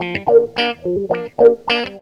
GTR 82 GM.wav